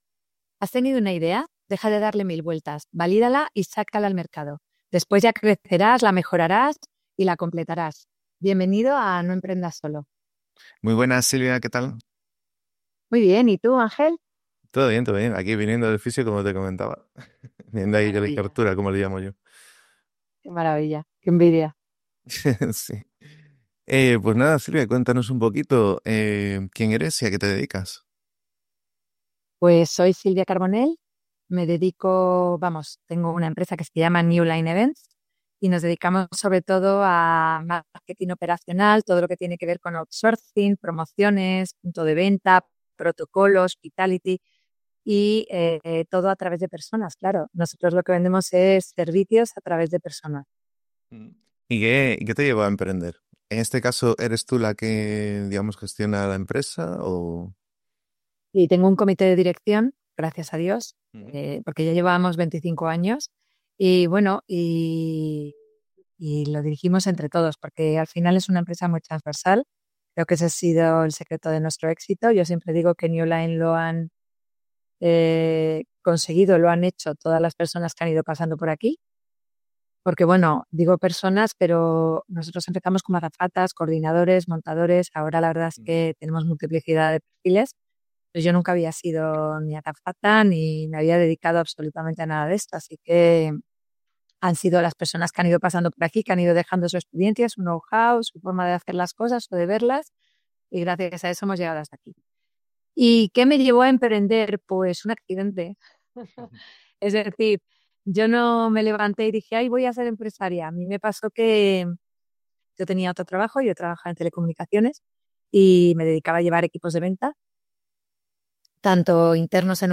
Una charla súper honesta, con los pies en la tierra y llena de lecciones para cualquiera que esté en el barro del emprendimiento o pensando en meterse.